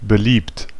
Ääntäminen
Synonyymit respected Ääntäminen US Haettu sana löytyi näillä lähdekielillä: englanti Käännös Ääninäyte Adjektiivit 1. beliebt Admired on sanan admire partisiipin perfekti.